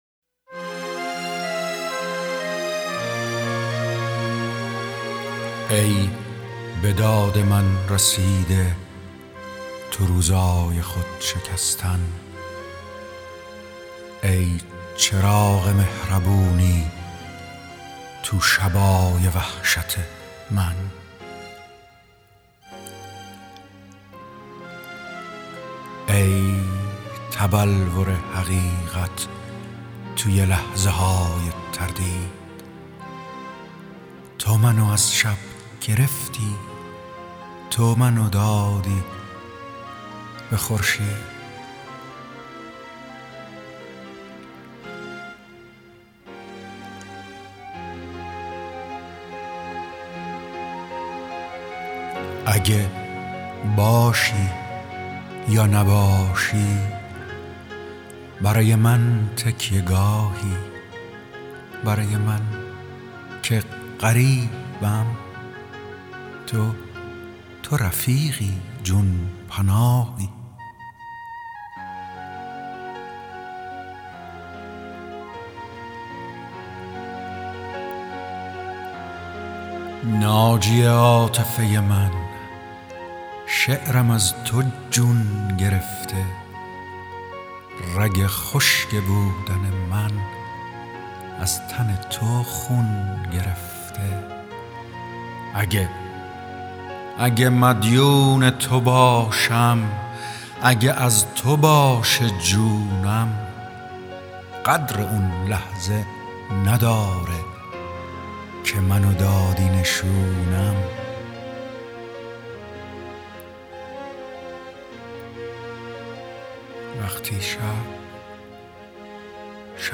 دانلود دکلمه یاور همیشه مؤمن با صدای ایرج جنتی عطایی با متن دکلمه
گوینده :   [ایرج جنتی عطایی]